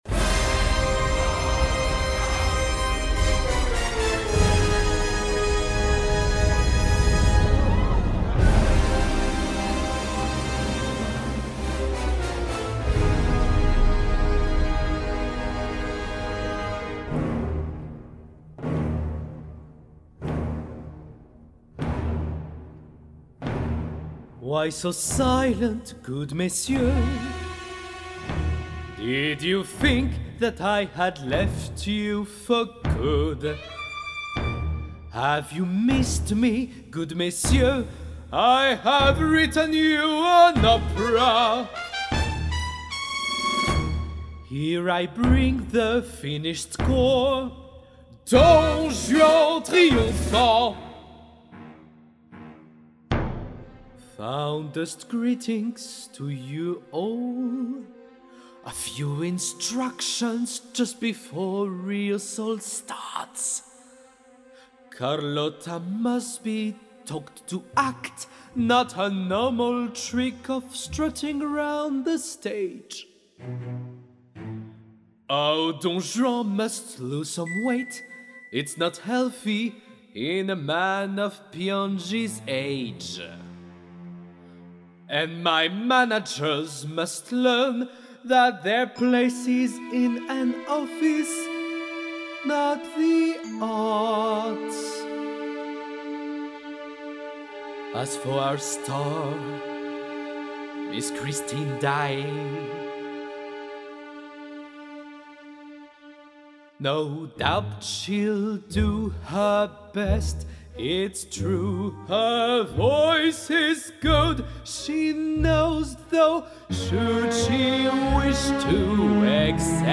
25 - 35 ans - Baryton